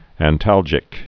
(ănt-ăljĭk)